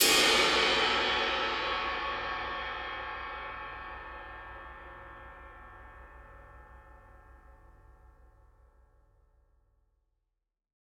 Index of /musicradar/Cymbals
CYCdh_MultiCrashLo-01.wav